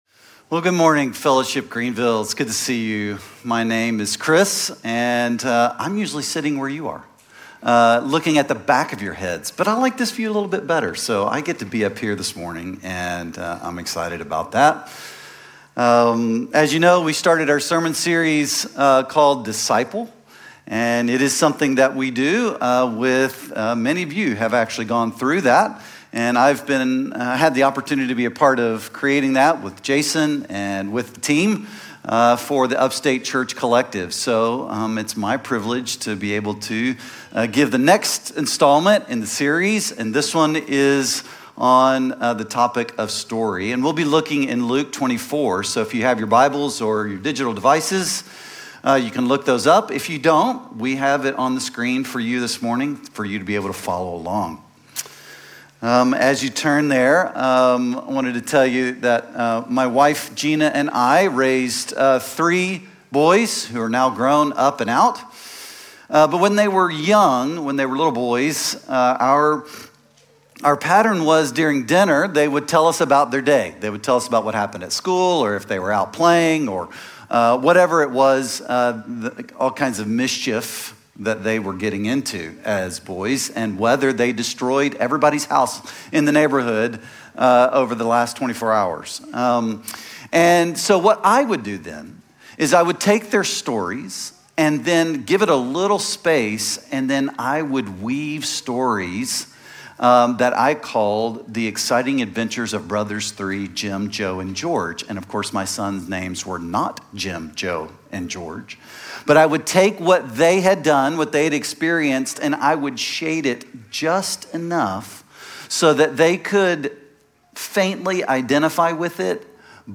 No signup or install needed. 2025 Standalone Sermons: Next Gen Sunday. 2025 Standalone Sermons: Next Gen Sunday.